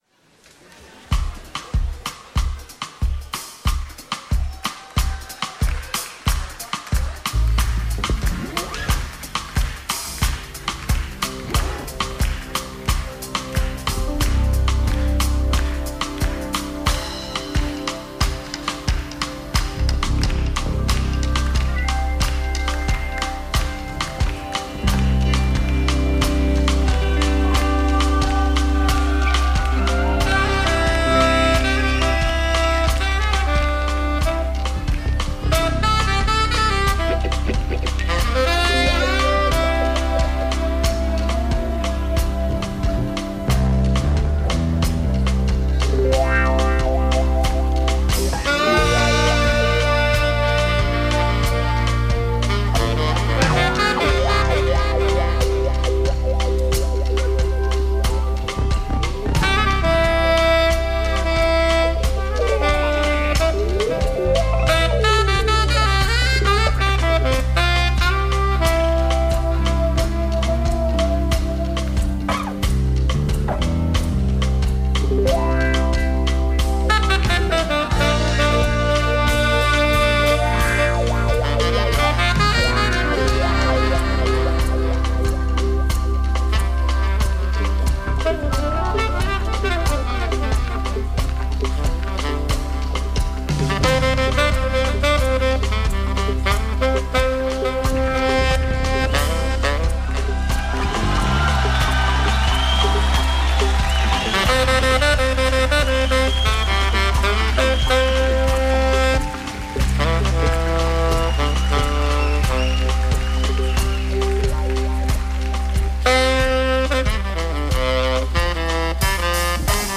Undisputed King of Afro-Jazz, Afro-Funk, Afro-Beat.